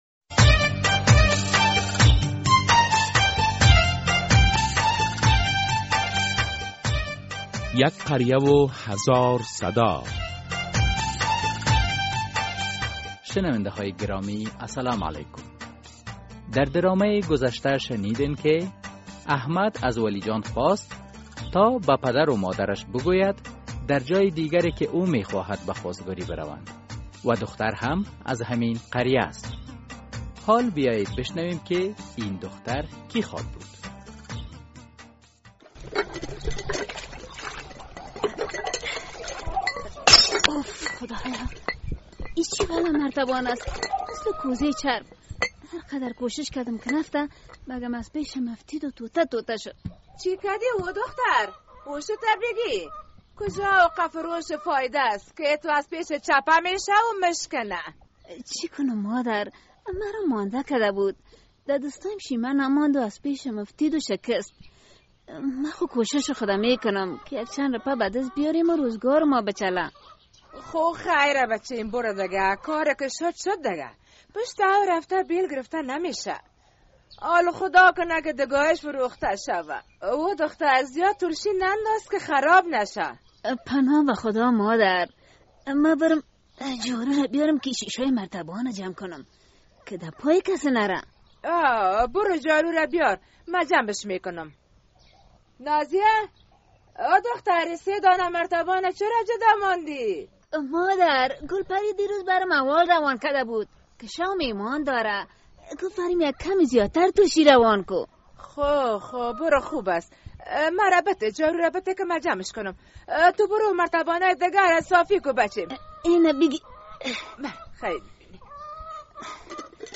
در این درامه که موضوعات مختلف مدنی، دینی، اخلاقی، اجتماعی و حقوقی بیان می گردد هر هفته به روز های دوشنبه ساعت 3:30 عصر از رادیو آزادی نشر می گردد.